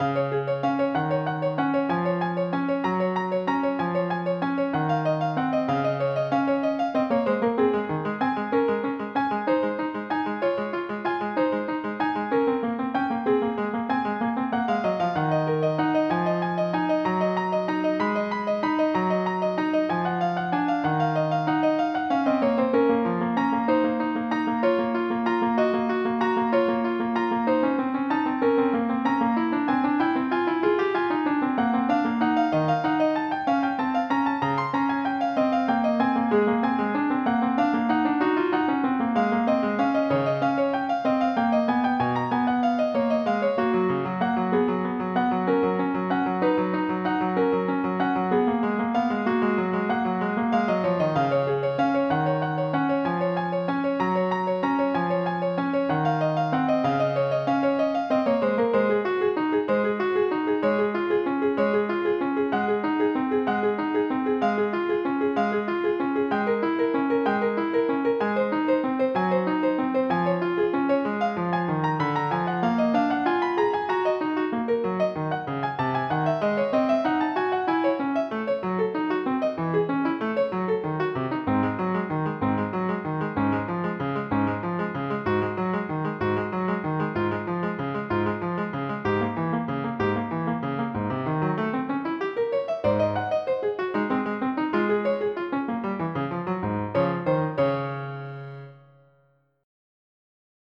MIDI Music File
PRELUD3.mp3